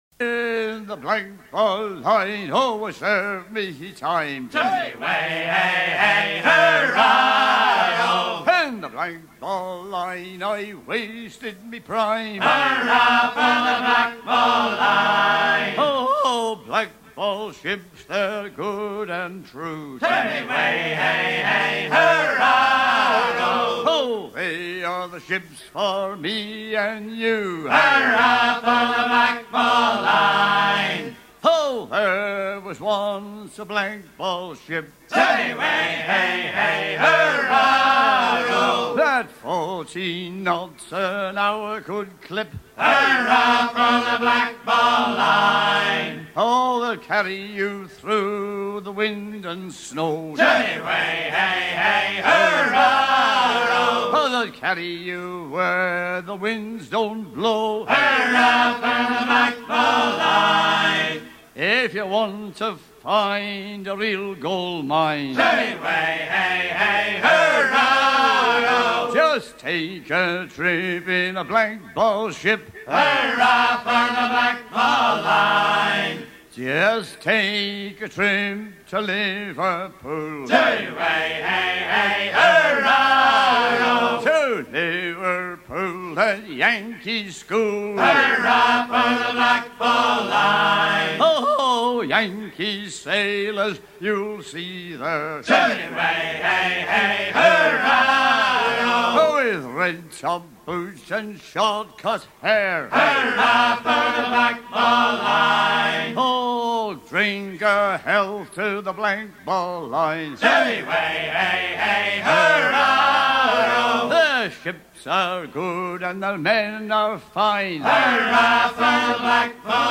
The blackball line Détails de l'archive Titre The blackball line Origine du titre : Editeur Note chanté sur les bateaux de Liverpool et sur les navires américains.
à virer au guindeau
Pièce musicale éditée